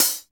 HAT REAL H21.wav